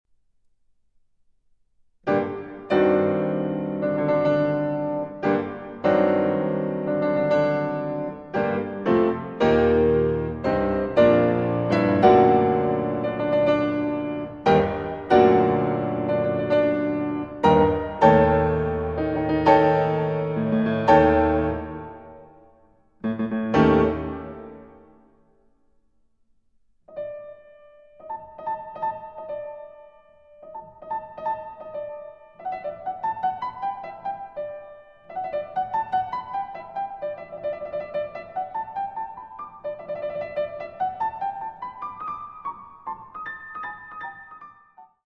Moderato, ma patetico e eroico in modo